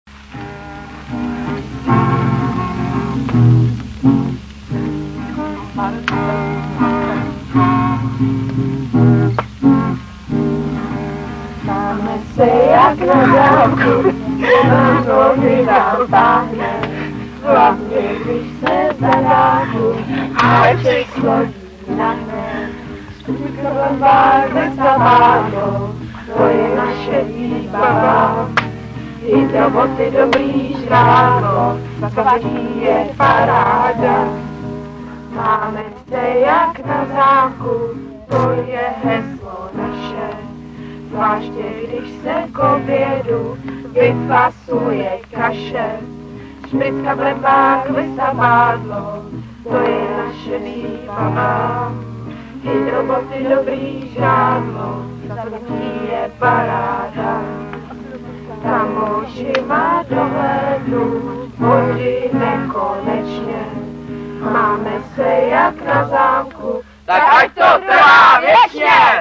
Jak už sám název praví, je to nezávislá autorsko-interpretační žabí soutěž, jejíž finále vypukne s železnou pravidelností vždy ke konci tábora.